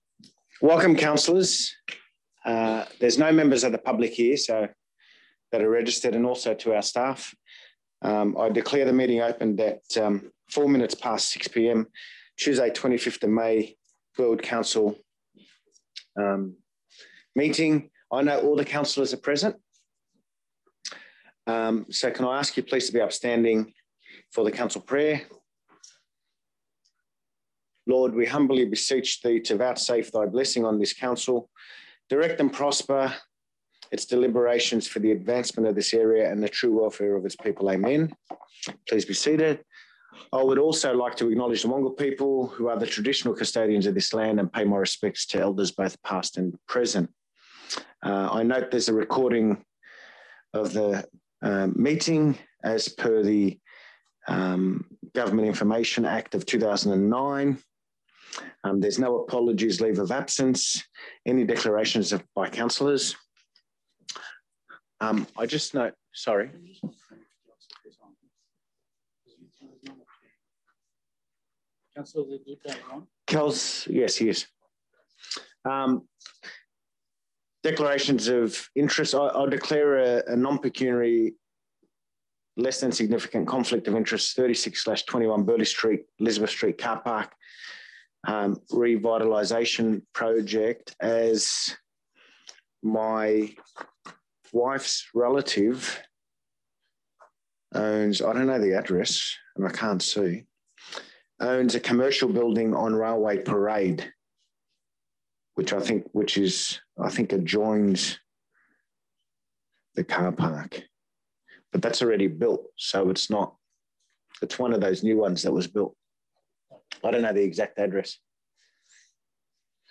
Council Meeting - 25 May 2021
Conference Room, 2 Conder Street, Burwood View Map